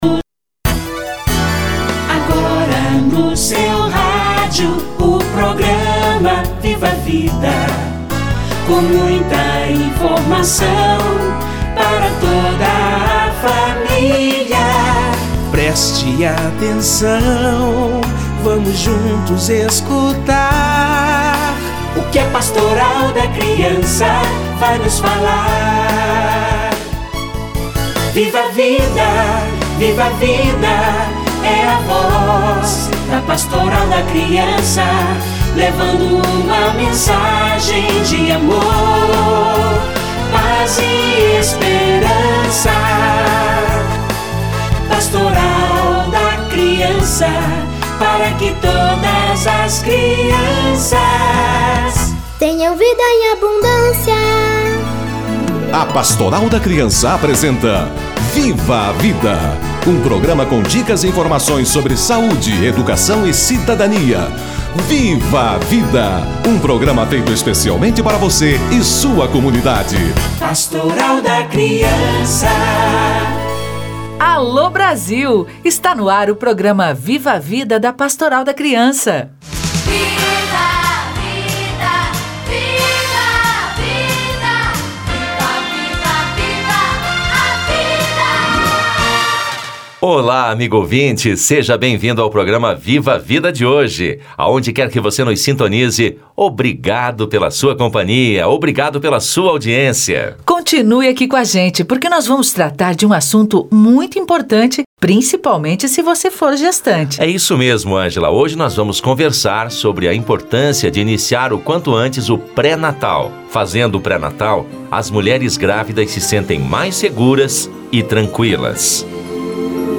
Mutirao em busca das gestantes - Entrevista